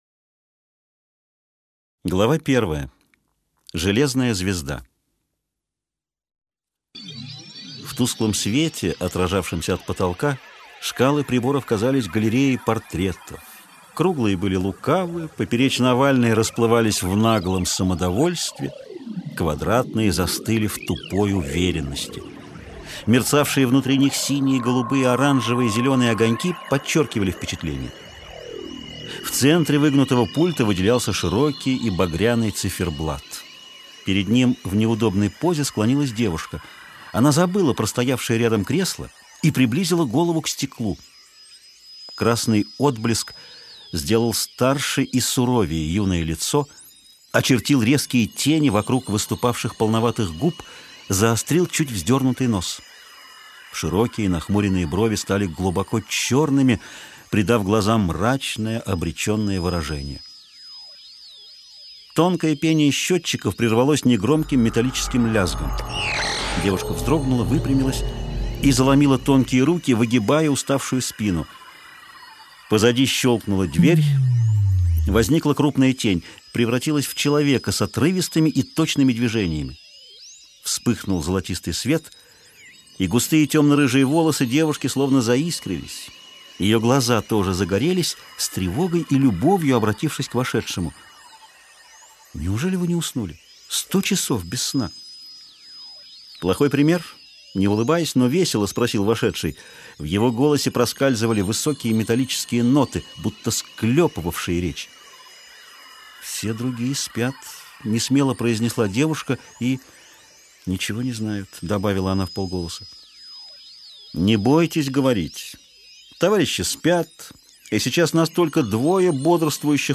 Аудиокнига Туманность Андромеды - купить, скачать и слушать онлайн | КнигоПоиск